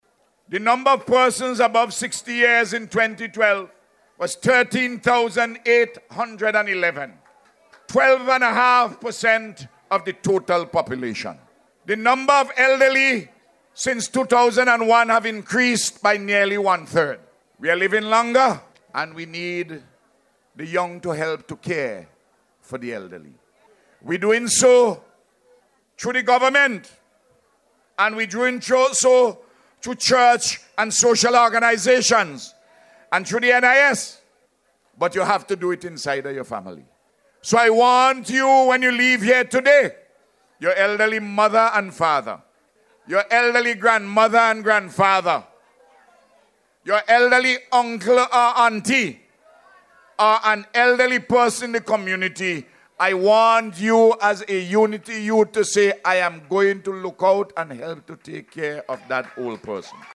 The Prime Minister raised the issue while addressing hundreds of young people at the ULP Youth Arm Convention on Sunday at the Thomas Saunders Secondary School.